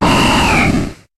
Cri de Barbicha dans Pokémon HOME.